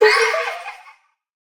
item_given1.ogg